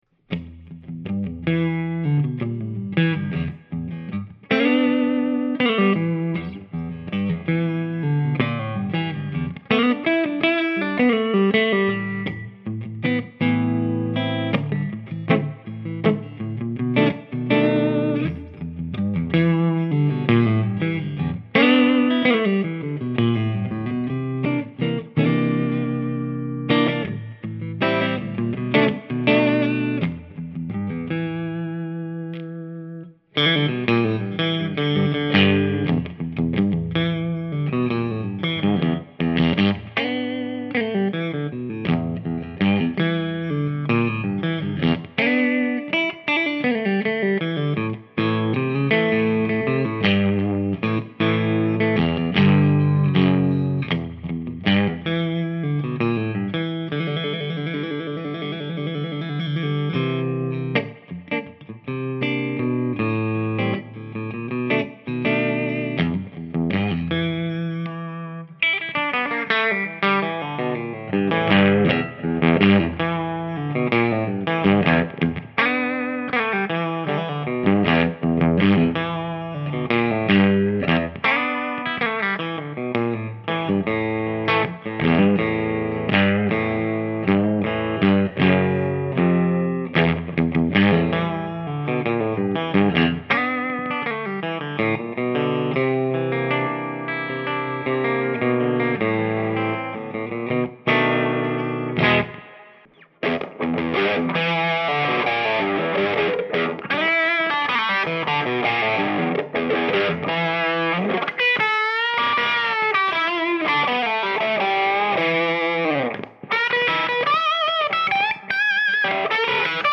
New Spalt Nouveau Series Tiffany Bon-Bon Mahogany/Yellowheart/Walnut - Dream Guitars
P-90s with Alnico Magnets in Bridge and Ceramic Magnets in Neck.